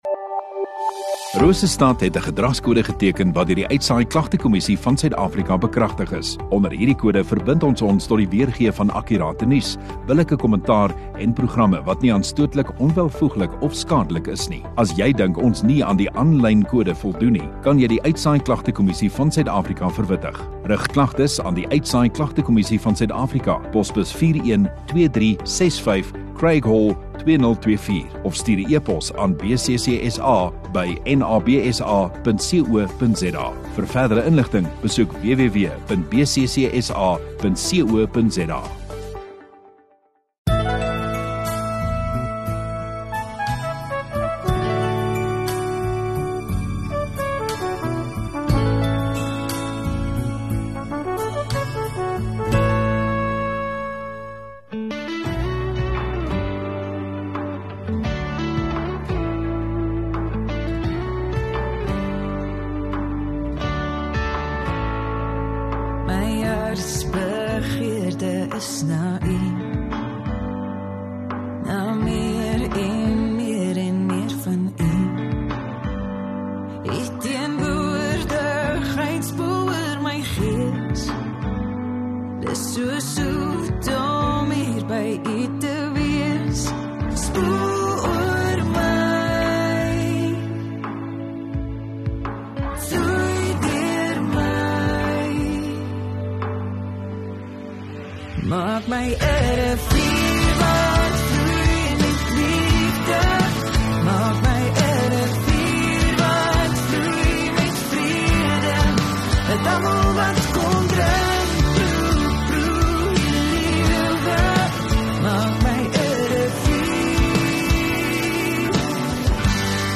23 Nov Sondagoggend Erediens